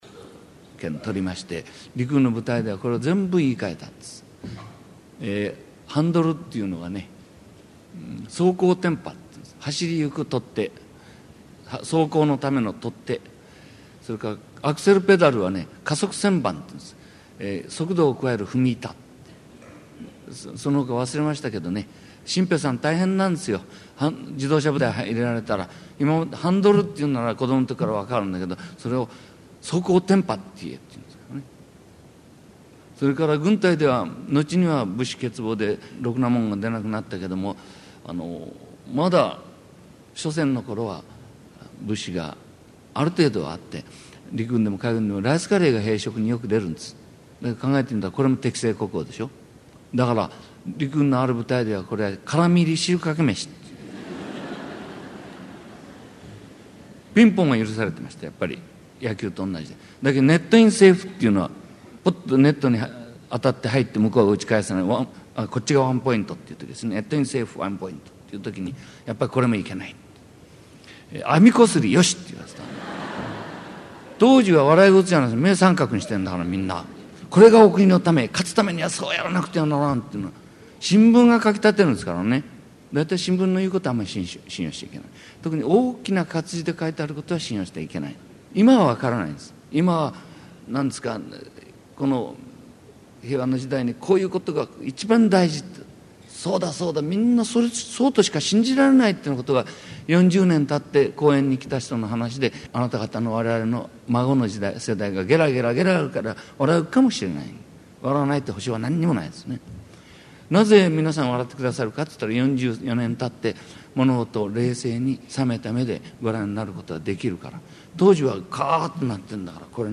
名だたる文筆家が登場する、文藝春秋の文化講演会。
（1989年5月18日 秋田市文化会館大ホール 菊池寛生誕百周年記念講演会より）